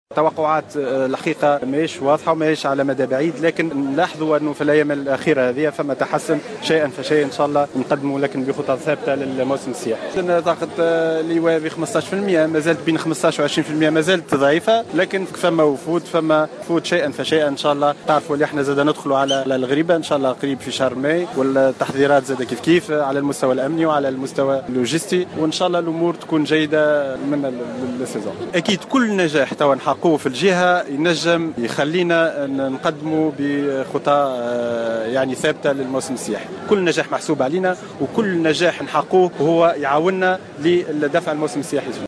أكد المندوب الجهوي للسياحة بمدنين أحمد الكلبوسي في إطار الملتقى الدولي للصورة التراثية بميدون جربة في تصريح لمراسلة الجوهرة "اف ام" اليوم الأحد 17 أفريل 2016 أن هناك تحسنا كبيرا في المؤشرات السياحية.